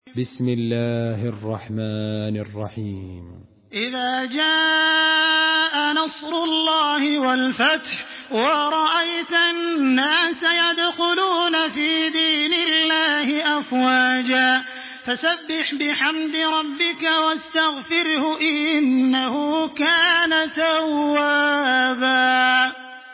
Surah An-Nasr MP3 in the Voice of Makkah Taraweeh 1426 in Hafs Narration
Listen and download the full recitation in MP3 format via direct and fast links in multiple qualities to your mobile phone.
Murattal